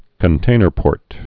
(kən-tānər-pôrt)